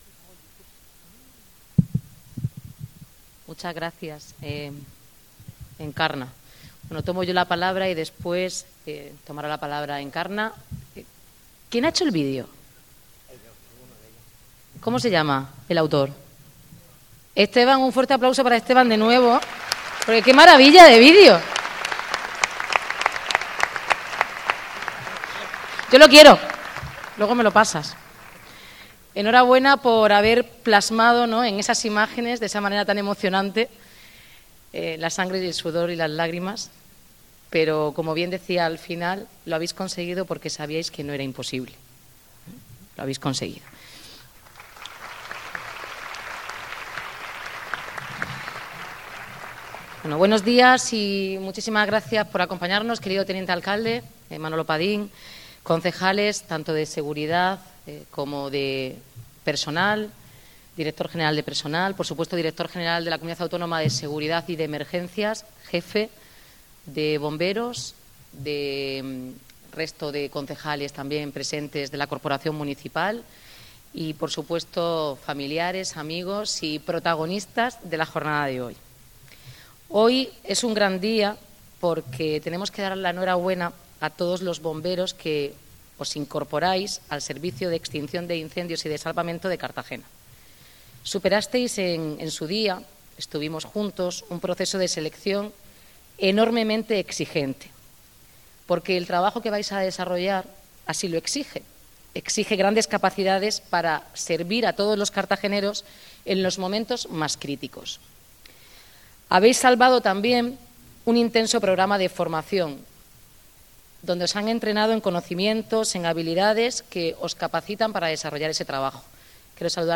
Enlace a Declaraciones Noelia Arroyo y acto de toma de posesión